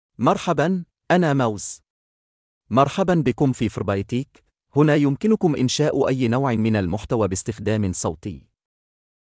MaleArabic (Qatar)
MoazMale Arabic AI voice
Moaz is a male AI voice for Arabic (Qatar).
Voice sample
Listen to Moaz's male Arabic voice.
Moaz delivers clear pronunciation with authentic Qatar Arabic intonation, making your content sound professionally produced.